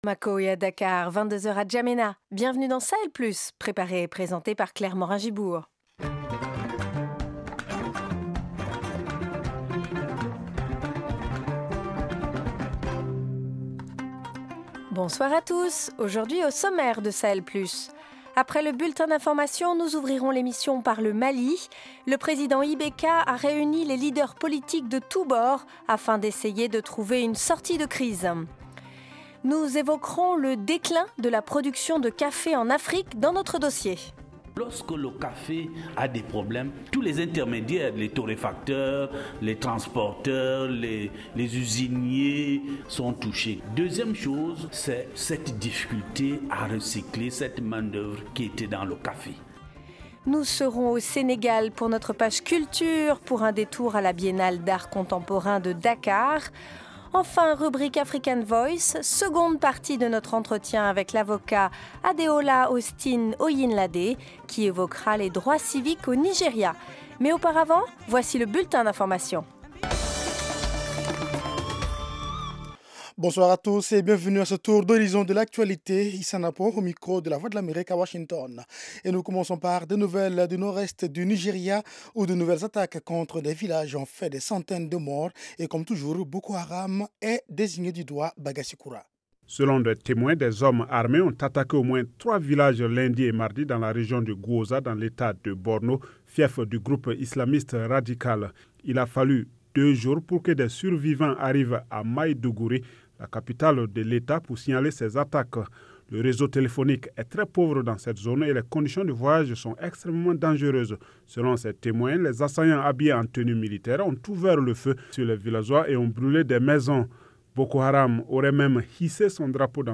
Au programme : au Mali, le président IBK a réuni les leaders politiques de tous bords afin d’essayer de trouver une sortie de crise. Dossier : la production de café décline fortement en Afrique. Page culture : la biennale d’art contemporain à Dakar au Sénégal. Rubrique african voice : seconde partie de l’entretien